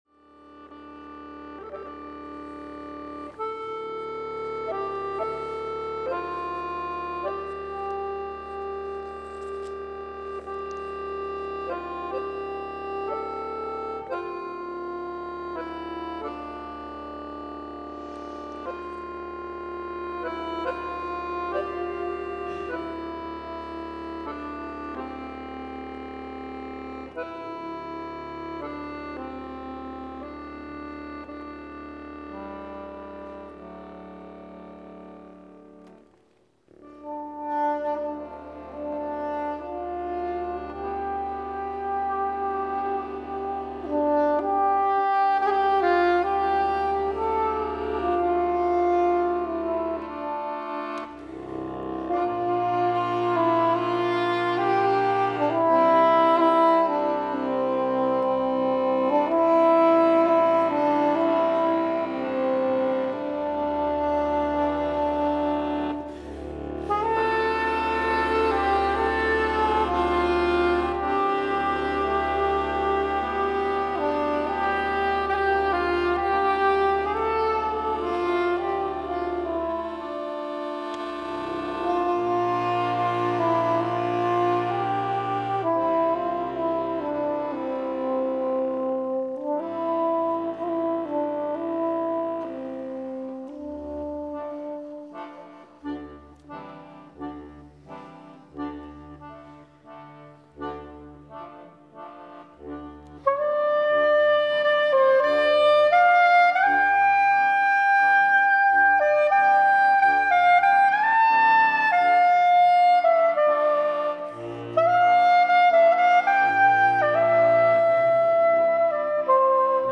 2025 Nagovor · Gedenkrede zumNachhören